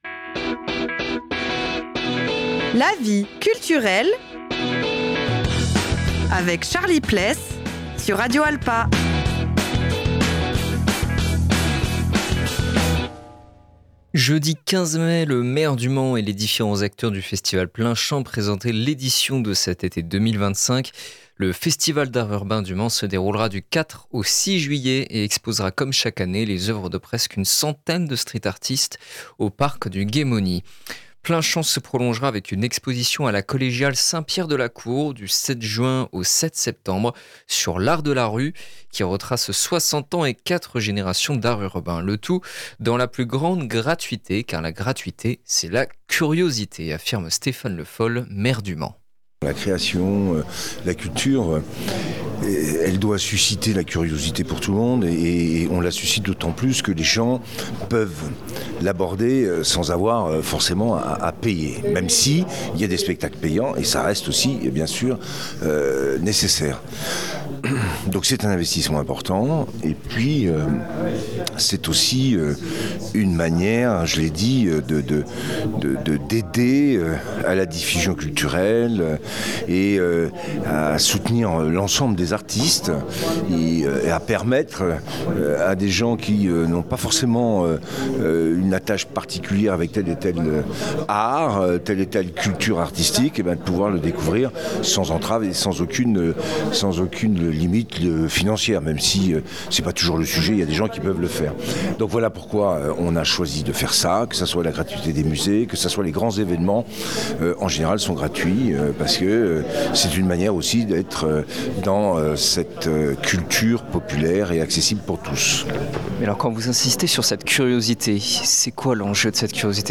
Jeudi 15 mai, le maire du Mans et les différents acteurs du festival Plein champ présentaient l’édition de cet été 2025.